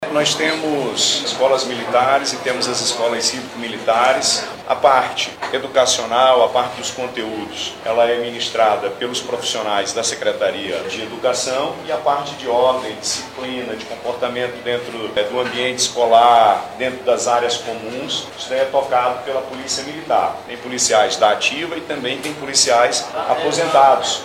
Durante a solenidade, o governador Wilson Lima destacou que a iniciativa busca oferecer um ambiente mais seguro e estruturado para o aprendizado dos estudantes.